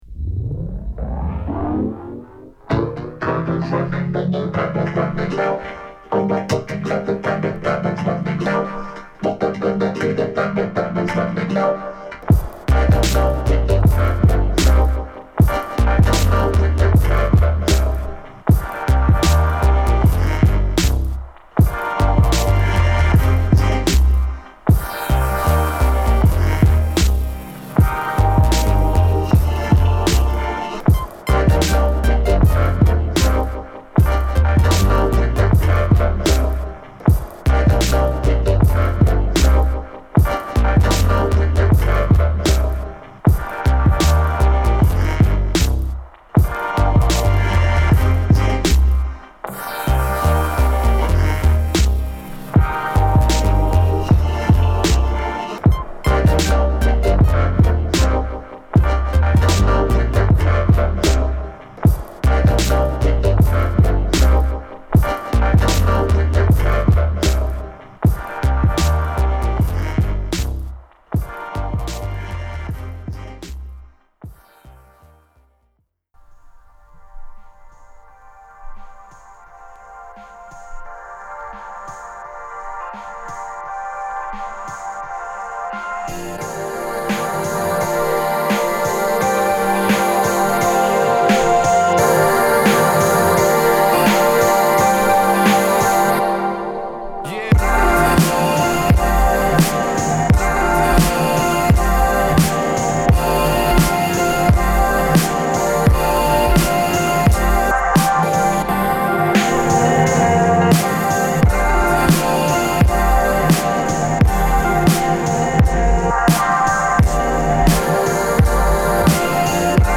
跳ねるエレクトロ・ビートB4
広がった上音のリヴァーブ感に降り落ちてくる太いキック＆スネアがかっこいいC3
自由を願った声ネタサンプルに痺れるC7
煌びやかなサウンドから土臭いサンプリングビート物まで収録した二枚組ビート集！